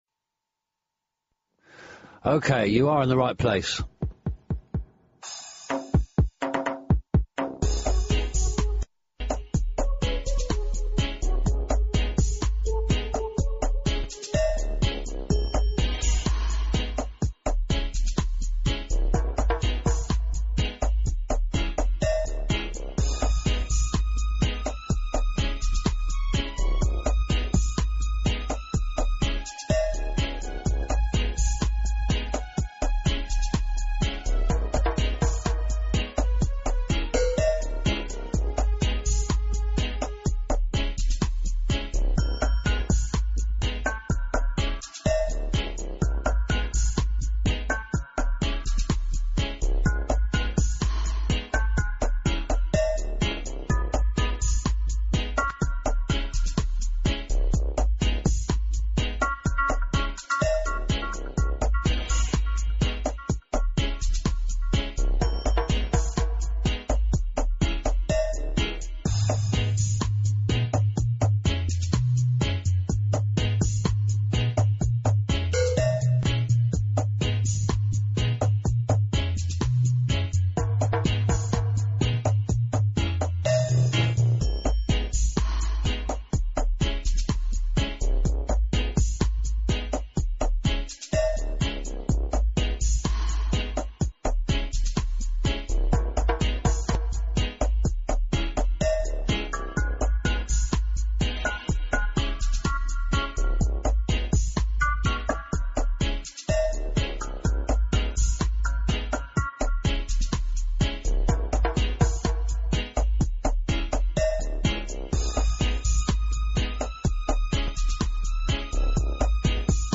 Roots reggae and other stuff in a dubby vein